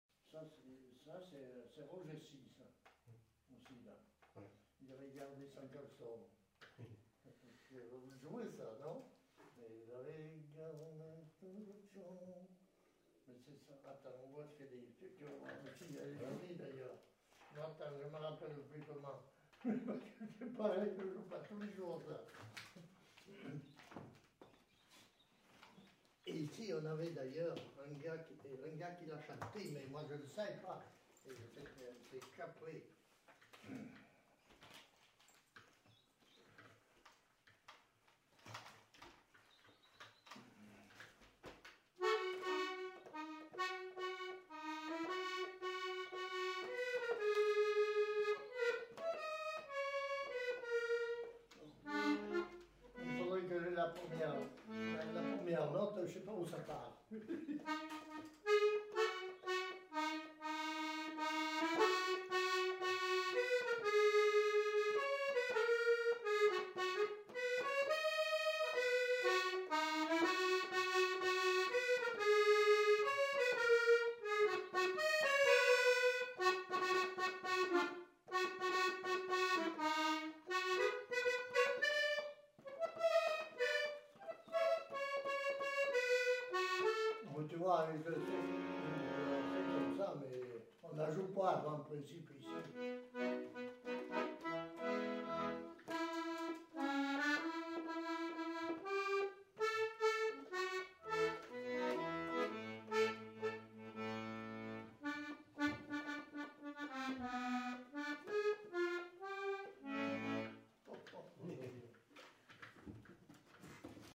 Aire culturelle : Quercy
Lieu : Chasteaux
Genre : morceau instrumental
Instrument de musique : accordéon chromatique